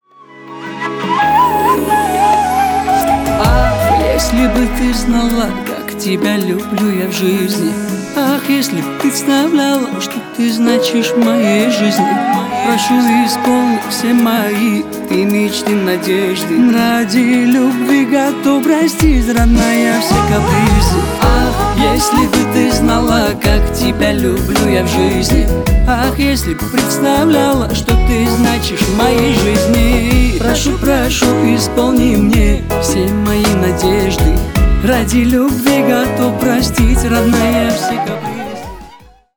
Поп Музыка
кавказские
грустные